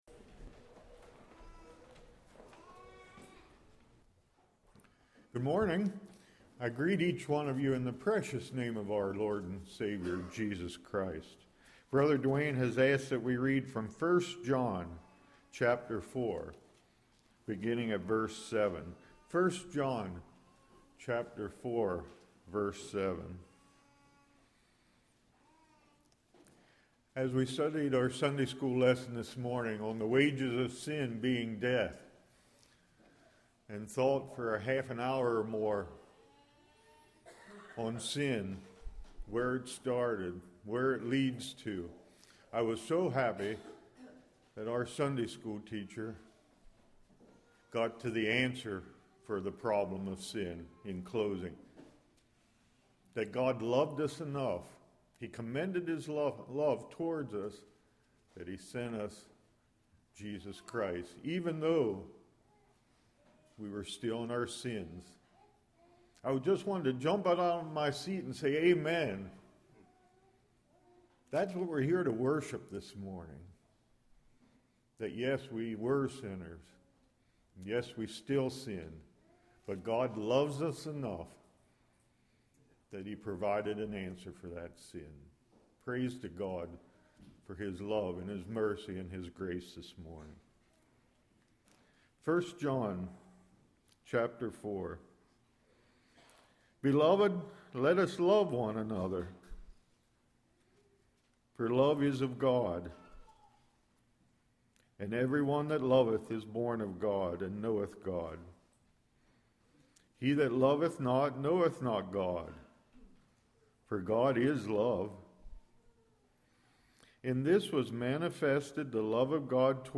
1 John 4:7-11 Service Type: Morning Defines Who We Are